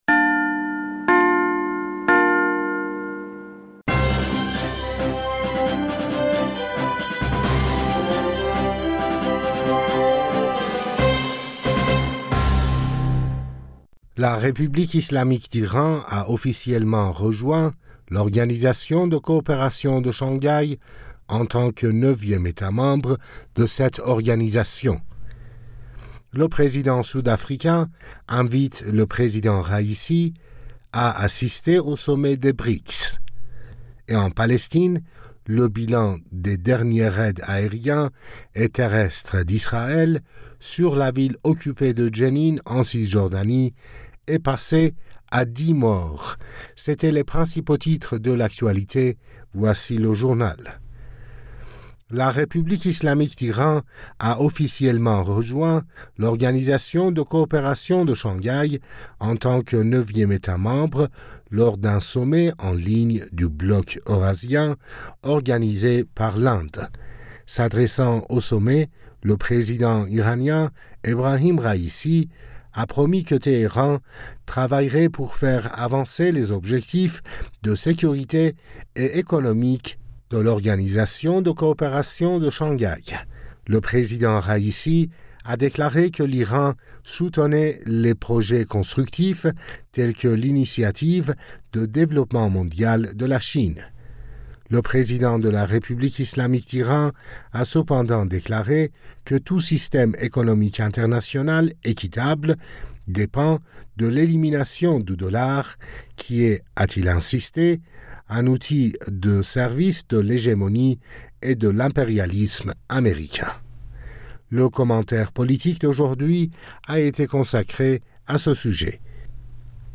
Bulletin d'information du 04 Juillet 2023